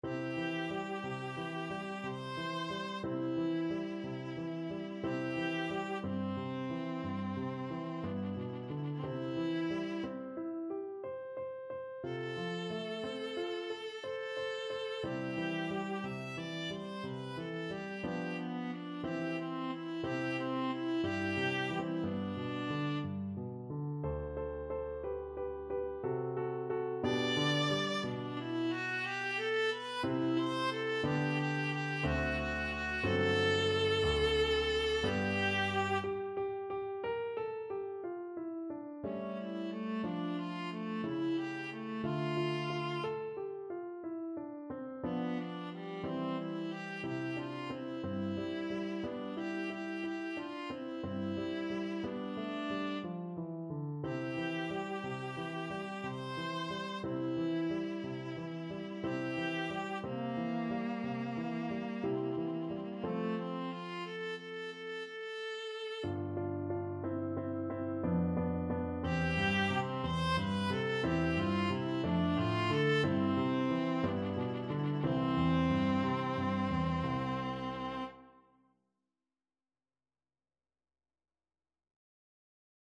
Andante =60
3/4 (View more 3/4 Music)
Classical (View more Classical Viola Music)